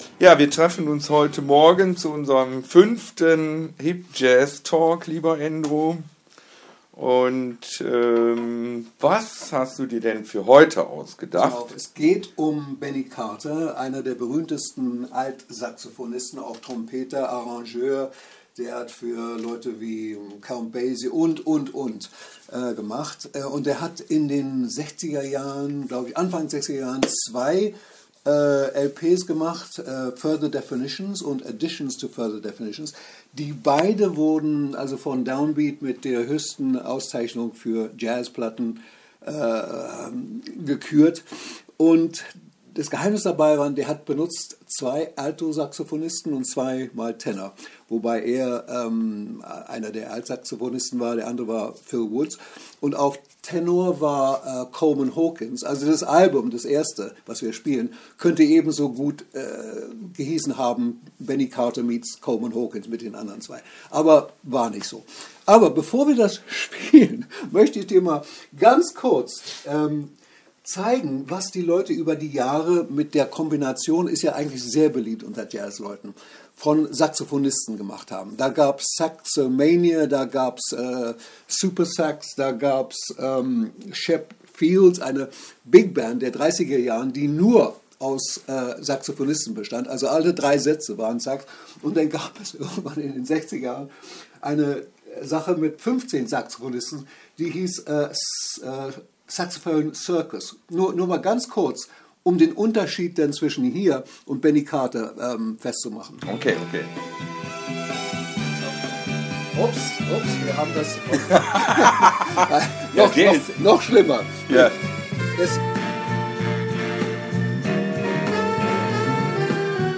Live Jazz hören und drüber sprechen.